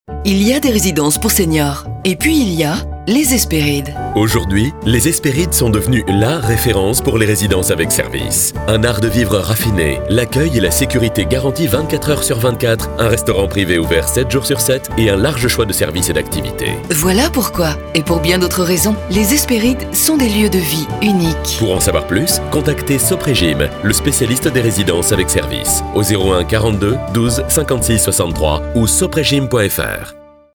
ECOUTEZ NOTRE SPOT RADIO NOTRE DAME!